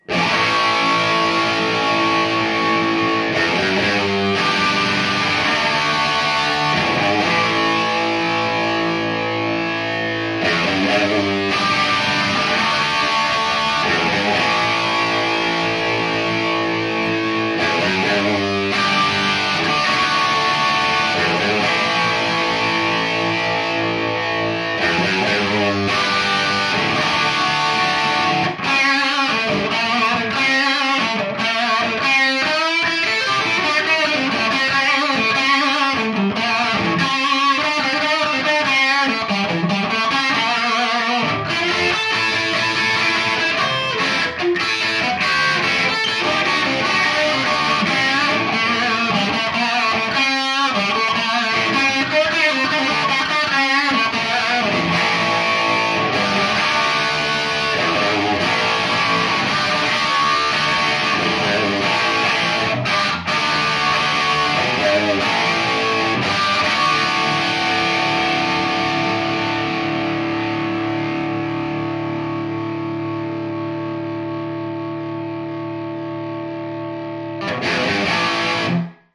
Le tout enregistré avec la même guitare, le même micro positionné au même endroit avec le même baffle (Rivera monté en Scumbacks).
La guitare est une vieille Les Paul Junior de '59, montée en P90 donc.
Soldano Hot Rod 50
Les Paul Junior et Soldano HotRod50 - 4.mp3